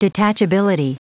Prononciation du mot detachability en anglais (fichier audio)
Prononciation du mot : detachability